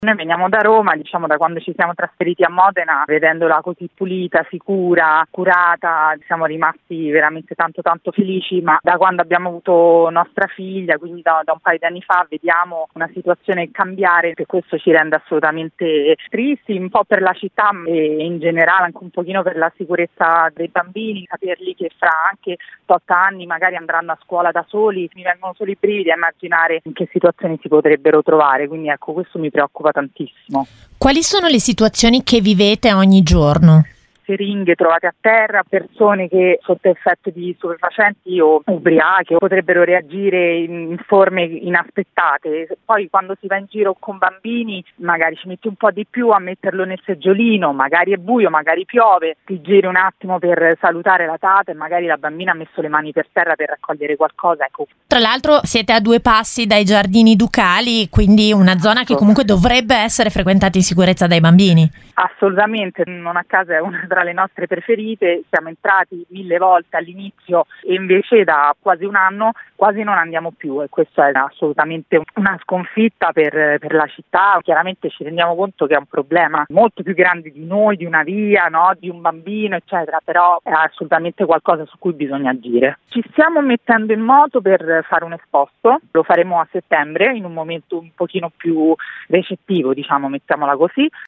Sentiamo una mamma residente in zona: